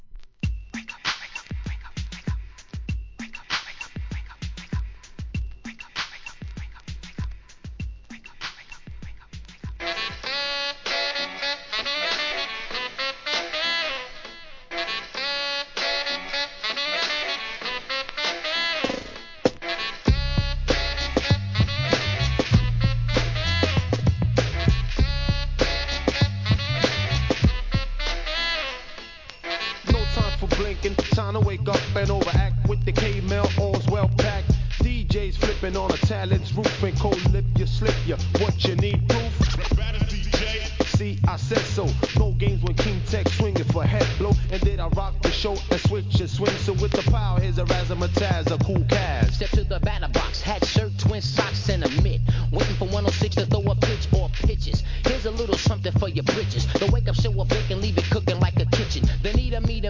HIP HOP/R&B
フリースタイルコンピシリーズ第1弾!!